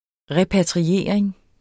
Udtale [ ˈʁεpatʁiˌeˀɐ̯eŋ ]